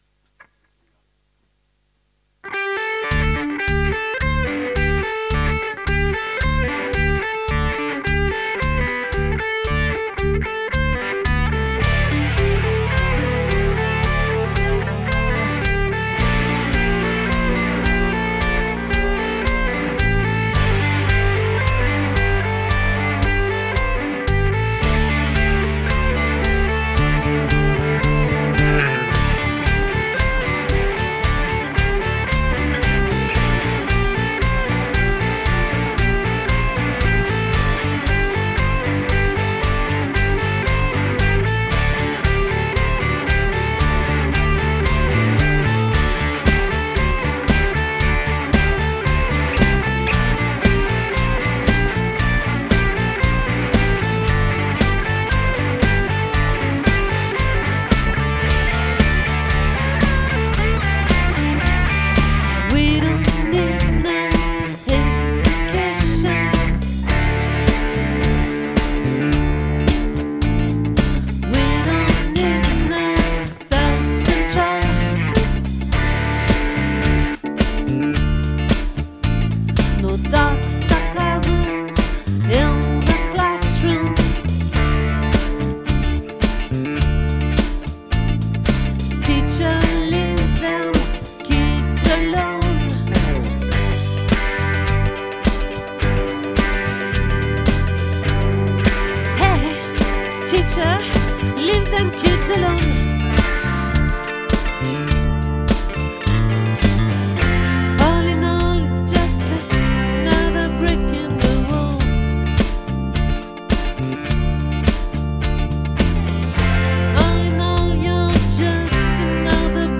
🏠 Accueil Repetitions Records_2025_02_03